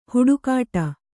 ♪ huḍukāṭa